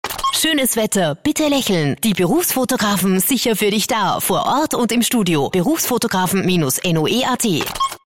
Die Niederösterreichischen Berufsfotografen sind jetzt auch im Radio!